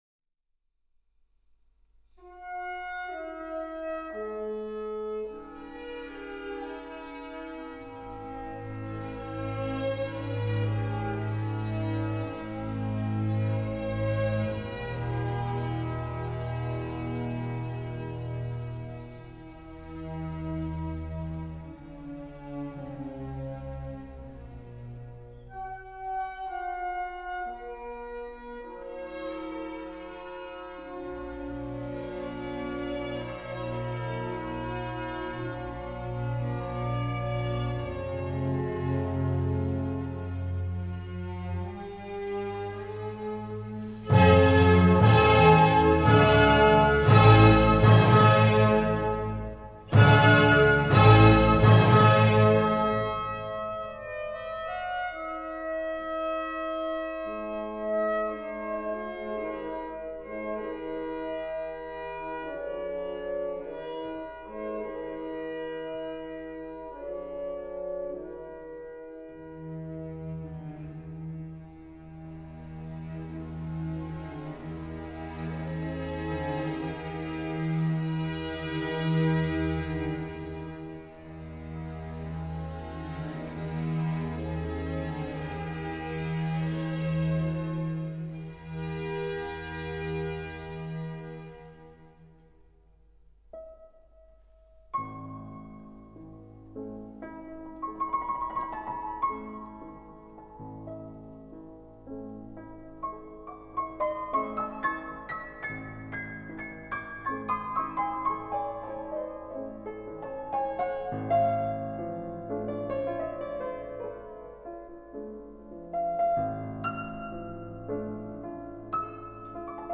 01 Fantasy on Polish Airs in A major Op. 13 1829